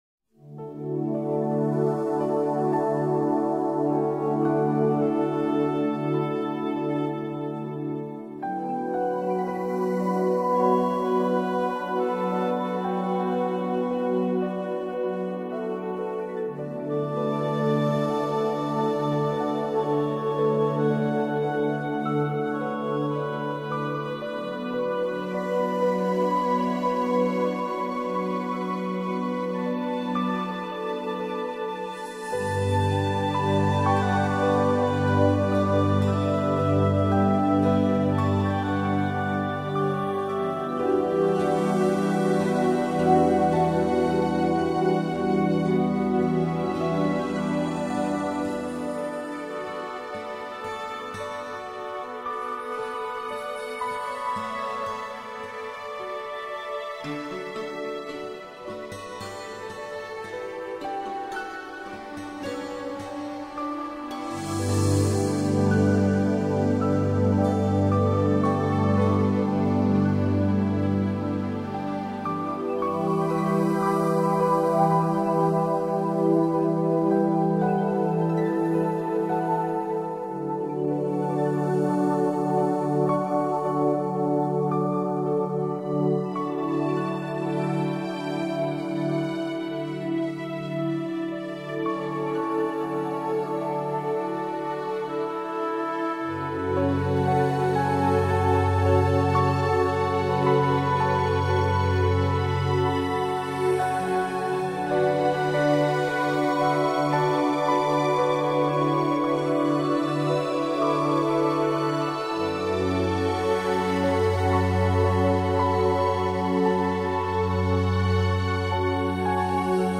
15-minutes-reiki-healing-meditation-music1.mp3